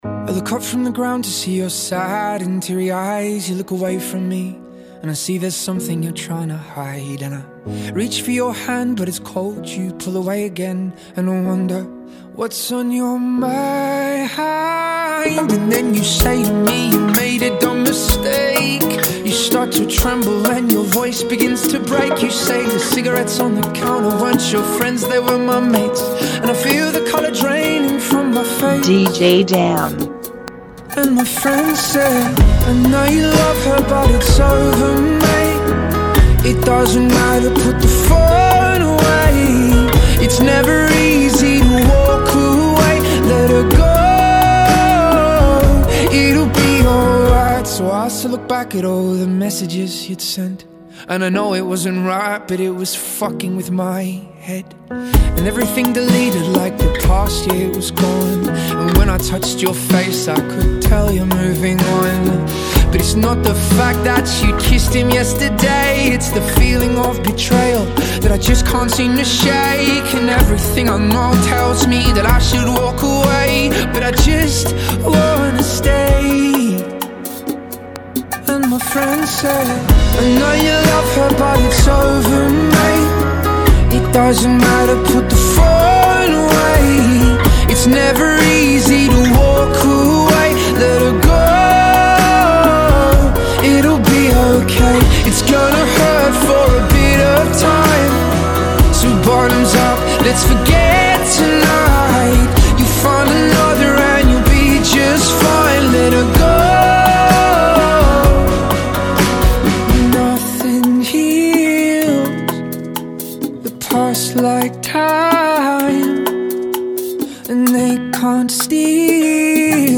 127 BPM
Genre: Bachata Remix